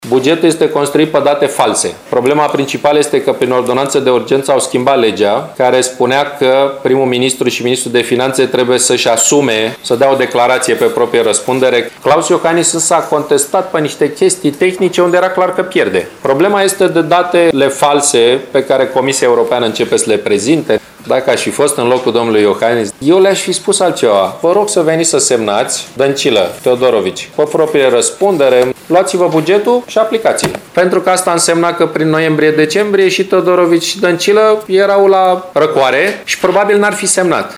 Cu acest prilej, a susținut o conferință de presă în cadrul căreia a declarat că respingerea bugetului de către președintele KLAUS IOHANNIS este “o acțiune greșită”.